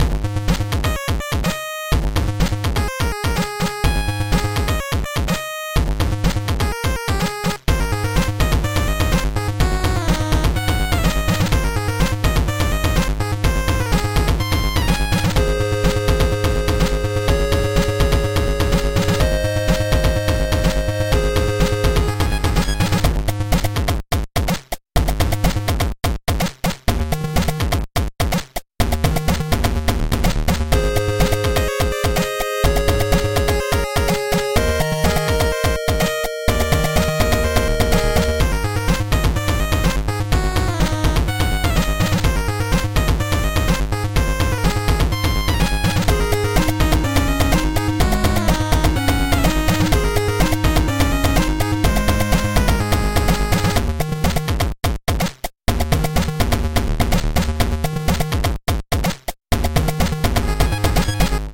semitechno.mp3